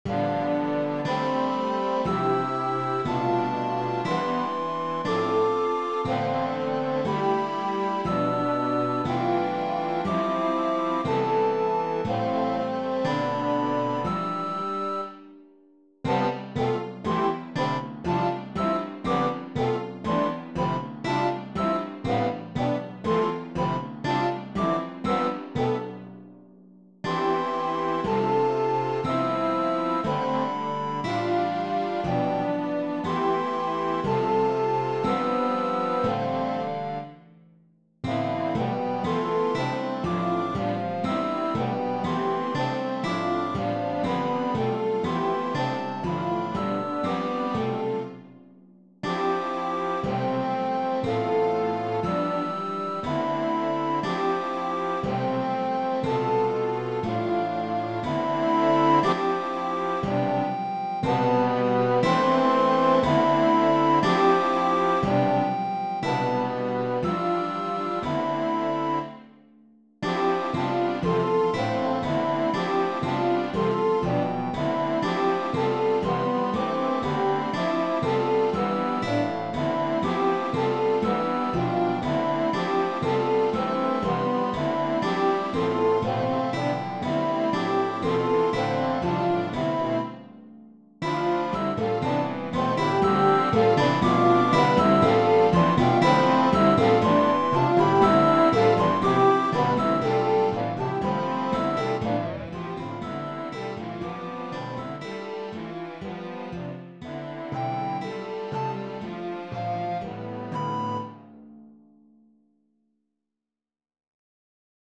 The Modern Symphony Music Prose Original Compostion.